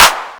Index of /90_sSampleCDs/Club_Techno/Percussion/Clap
Clap_10.wav